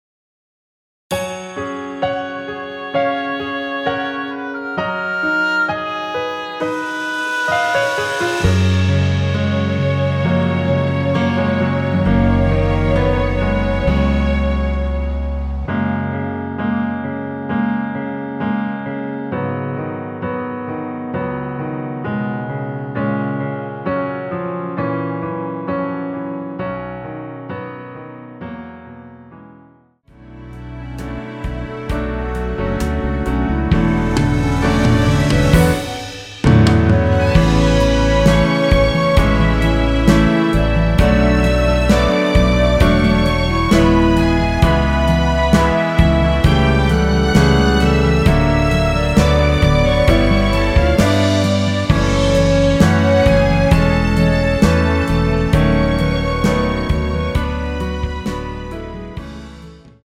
원키에서 (-4)내린 멜로디 포함된 MR 입니다.
F#
앞부분30초, 뒷부분30초씩 편집해서 올려 드리고 있습니다.
중간에 음이 끈어지고 다시 나오는 이유는